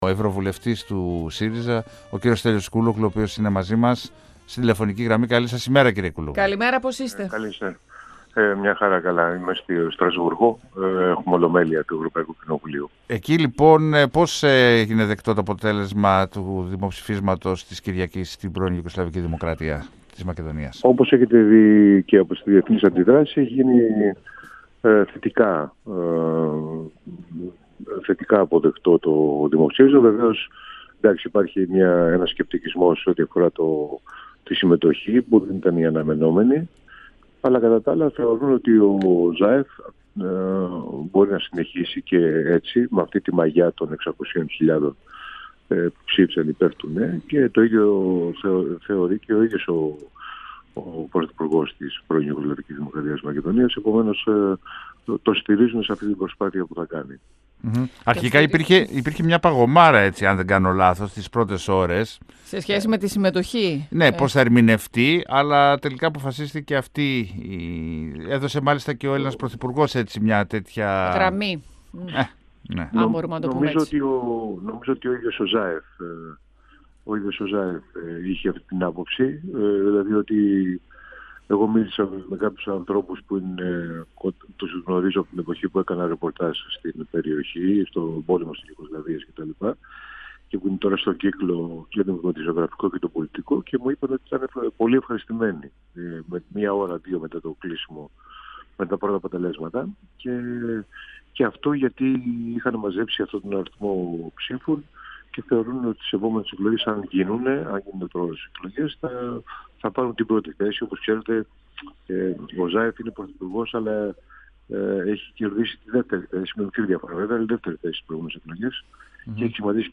Ο πρόεδρος της Νέας Δημοκρατίας, Κυριάκος Μητσοτάκης πάει για Νόμπελ υποκριτικής για το θέμα των Σκοπίων, υποστήριξε ο ευρωβουλευτής του ΣΥΡΙΖΑ Στέλιος Κούλογλου μιλώντας στον 102 του Ραδιοφωνικού Σταθμού Μακεδονίας της ΕΡΤ3 και στην εκπομπή «Μάθε τι Παίζει». Ο κ. Κούλογλου εκτίμησε ότι οι πιθανότητες να περάσει ο πρωθυπουργός της πΓΔΜ, Ζόραν Ζάεφ τη συμφωνία από τη βουλή των Σκοπίων ή να οδηγηθεί σε πρόωρες εκλογές είναι 50-50.